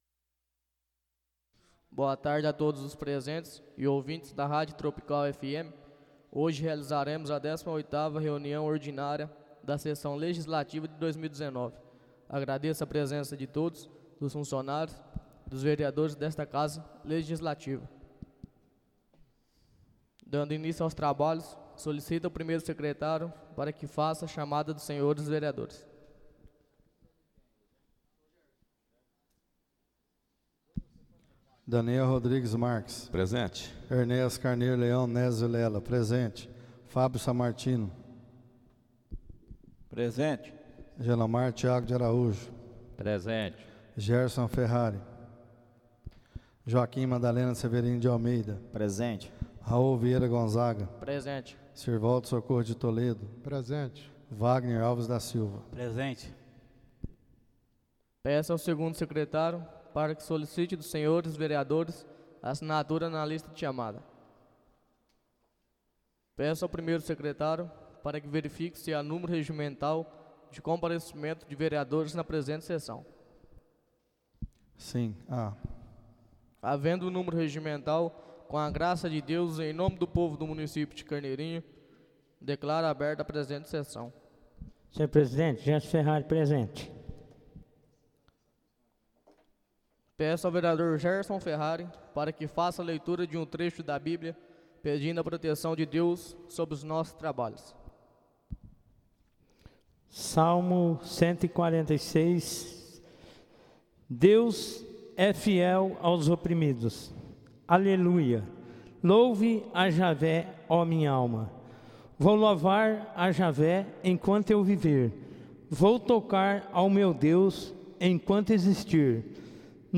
Áudio da 18.ª reunião ordinária de 2019, realizada no dia 18 de Novembro de 2019, na sala de sessões da Câmara Municipal de Carneirinho, Estado de Minas Gerais.